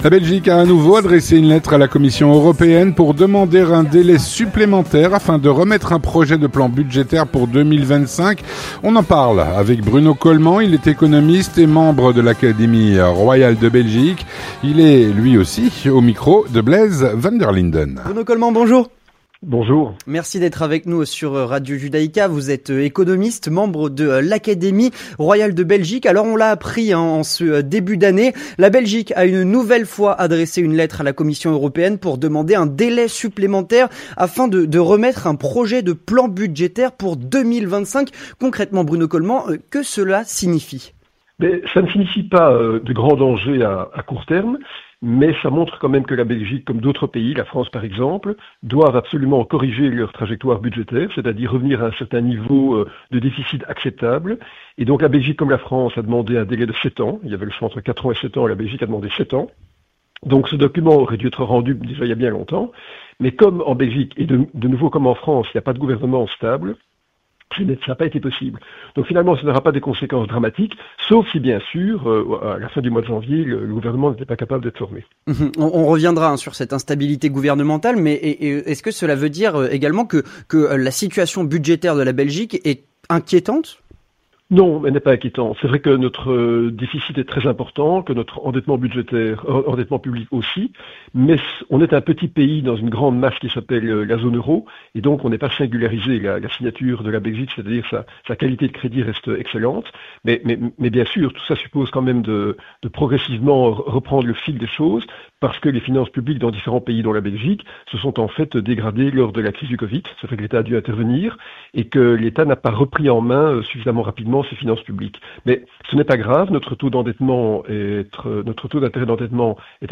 On en parle avec Bruno Colmant, économiste et membre de l’Académie royale de Belgique.